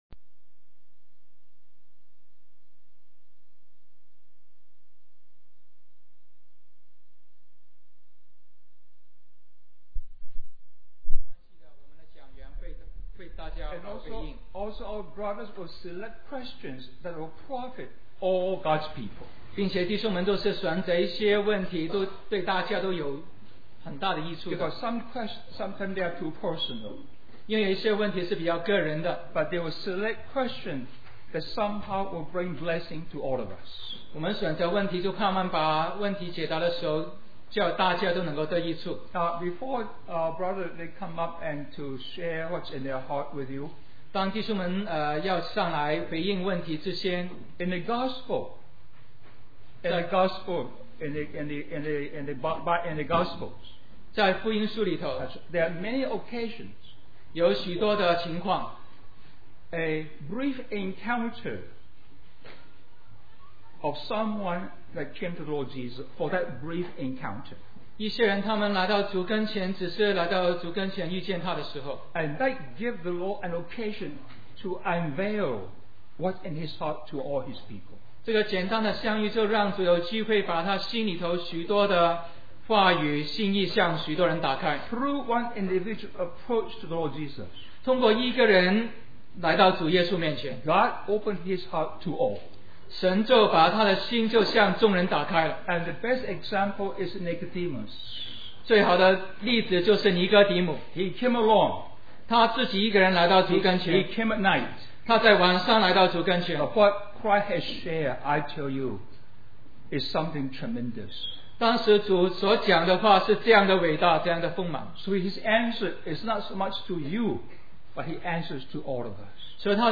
Spiritual Service Question & Answer Session
Special Conference For Service, Australia